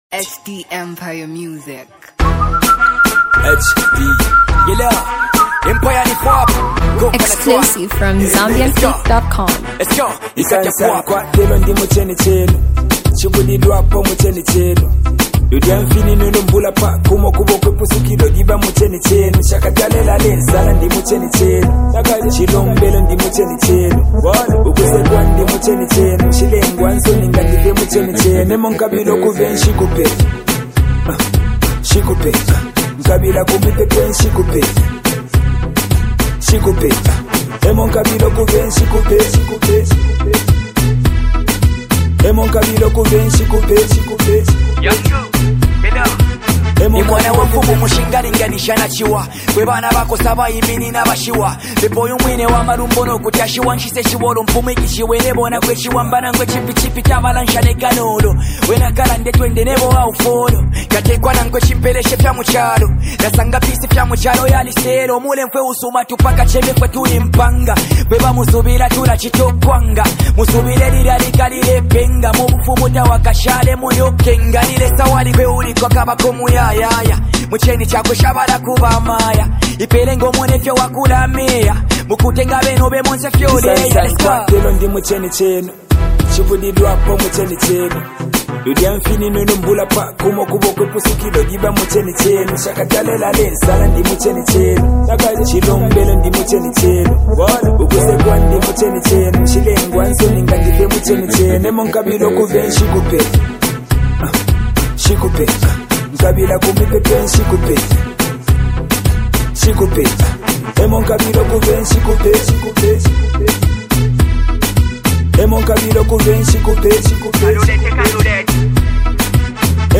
gospel secular music
hip-hop